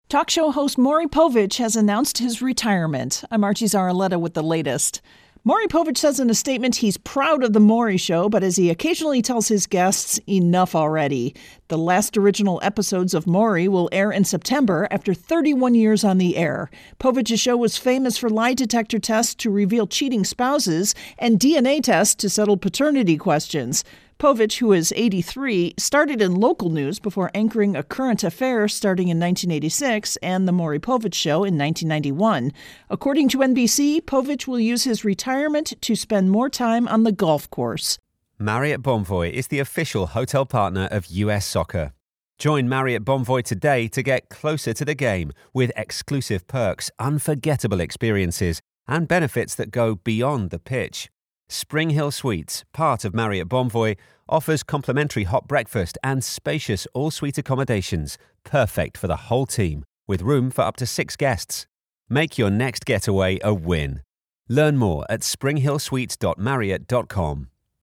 intro & voicer for Maury Povich